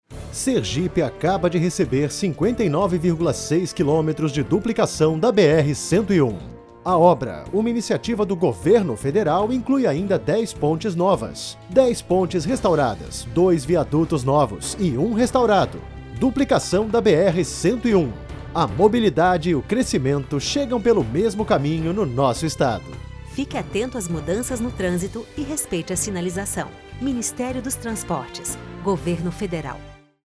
Min._dos_Transportes_-_Spot_-_Duplicação_BR_101_SE_v2.mp3